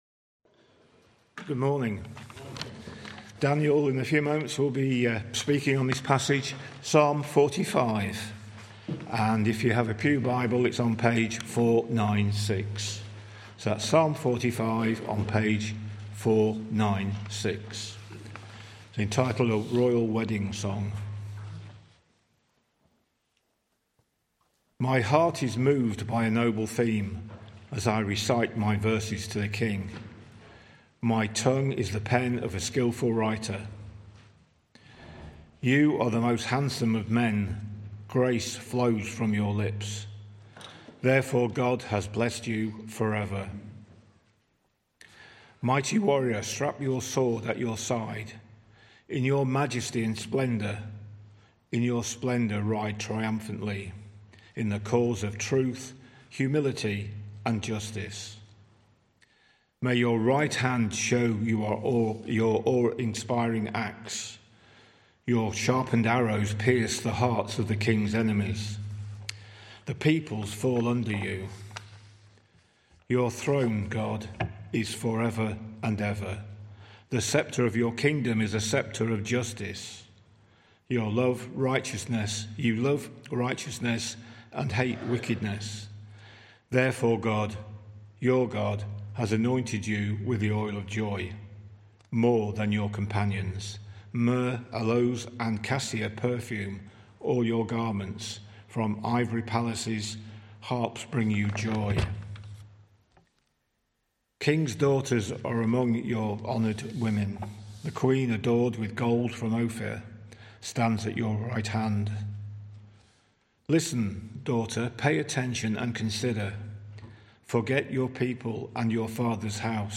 From Series: "Other Sermons"